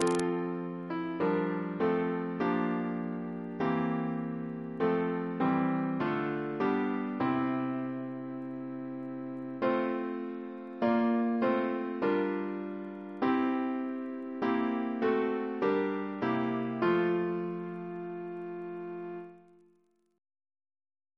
Double chant in F Composer: George C. Martin (1844-1916) Reference psalters: ACB: 34